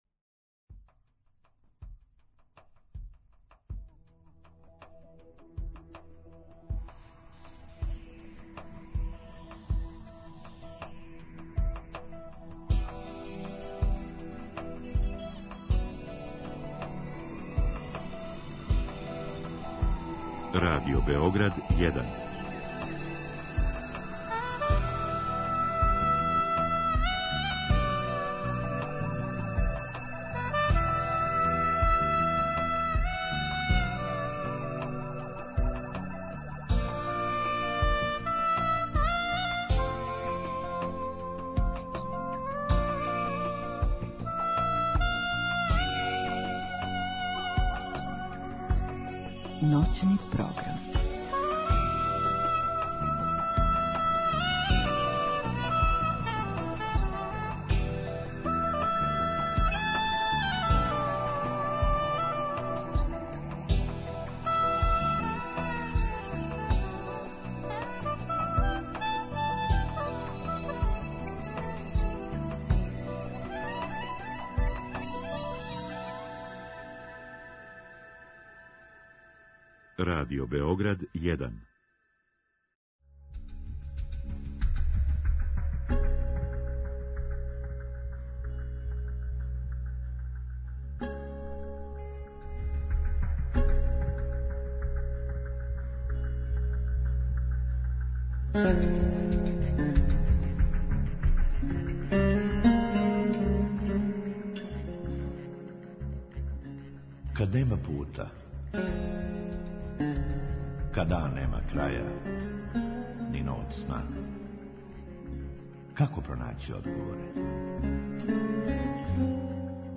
У другом сату слушаоци могу поставити питање гошћи директно у програму или путем Инстаграм стране емисије.